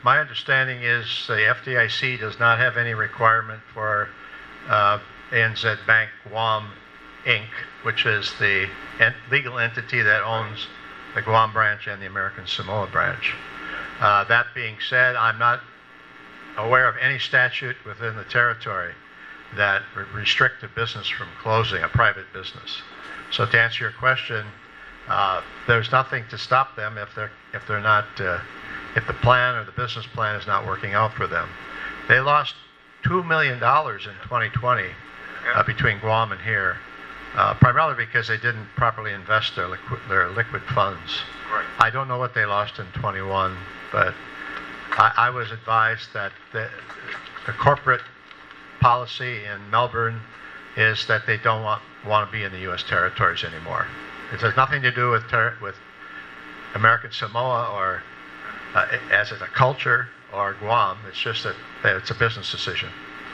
These were some of the questions that members of the House Government Operations Committee asked at a hearing yesterday into issues related to the Territorial Bank of American Samoa and the reported pending departure of ANZ.